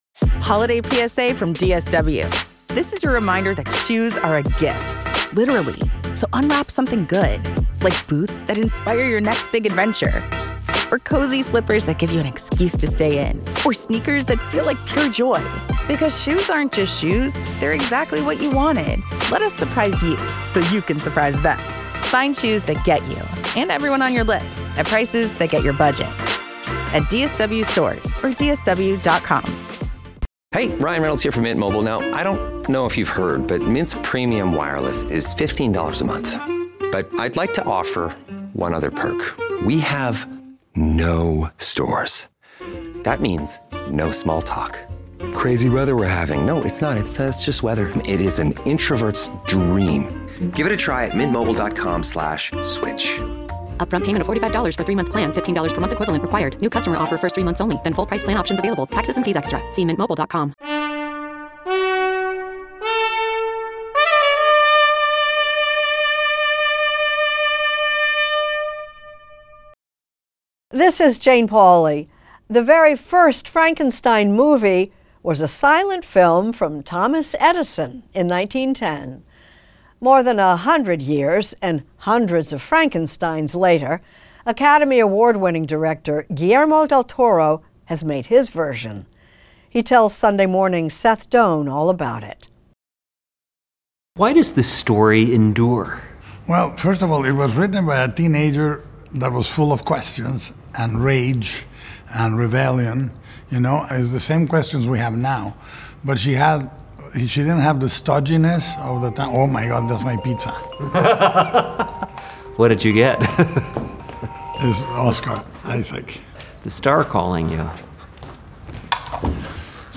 Extended Interview: Guillermo del Toro Podcast with Jane Pauley